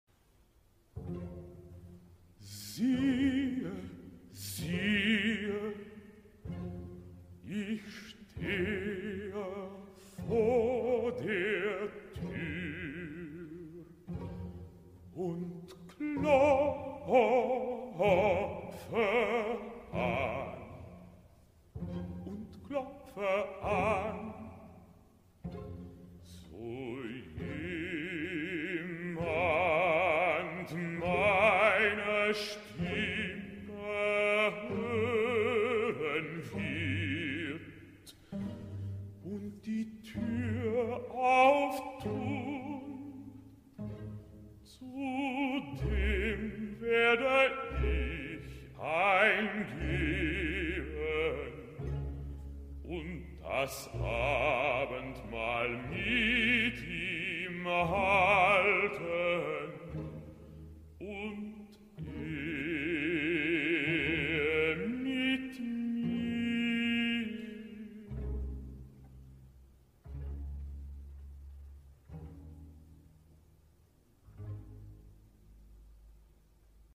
Baritono